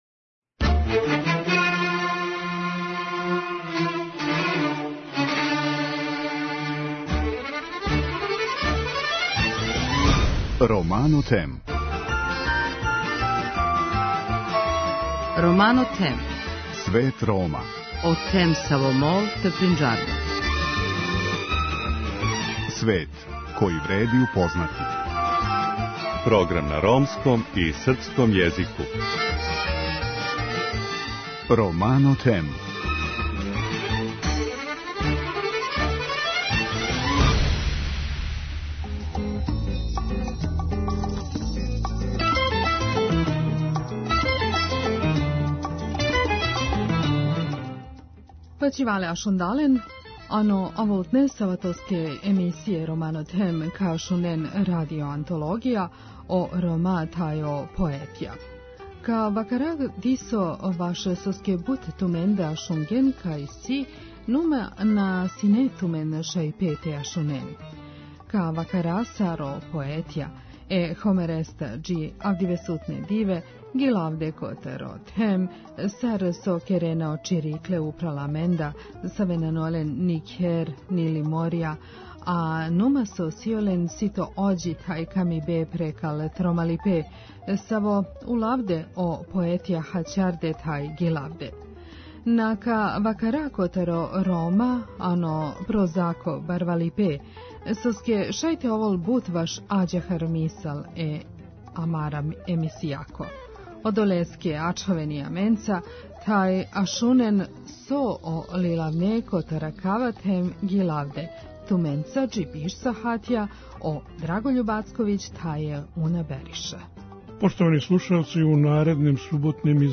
У суботњем издању Света Рома говоримо поезију значајних светских песника, али и песника са наших простора, који су писали о Ромима.